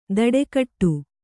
♪ daḍe kaṭṭu